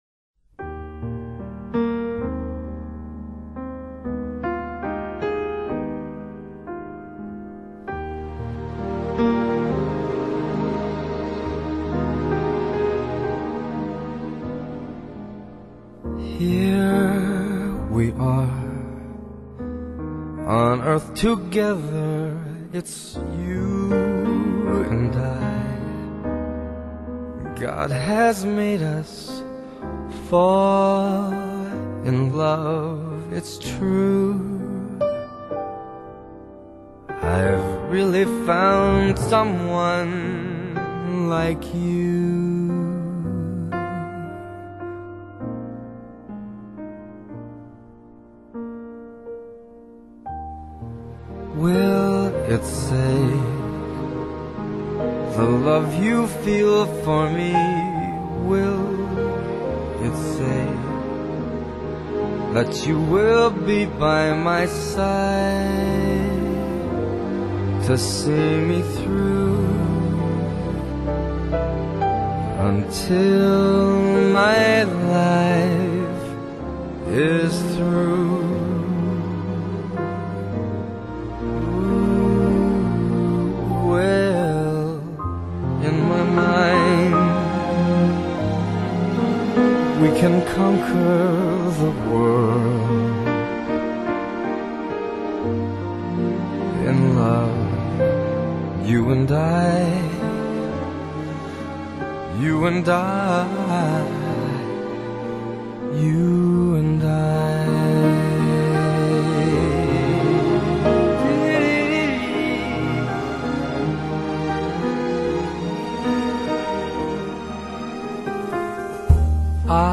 音乐类型：爵士乐